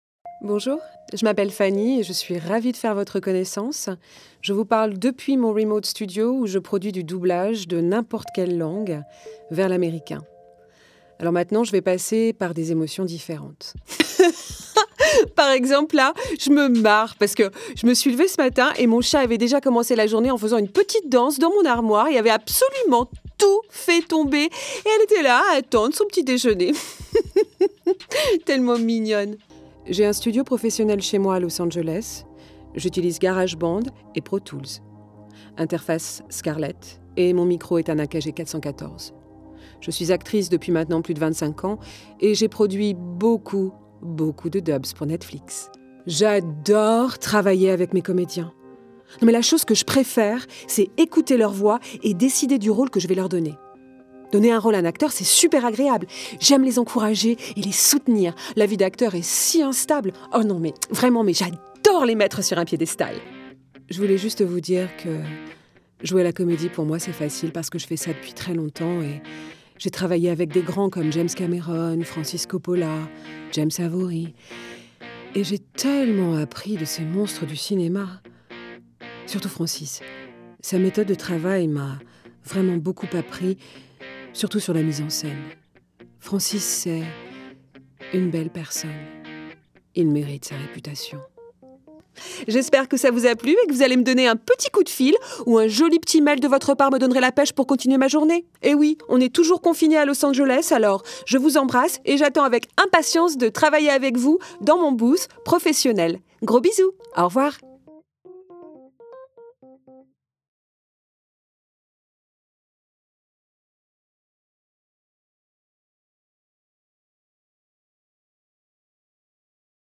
Voix off
27 - 72 ans - Mezzo-soprano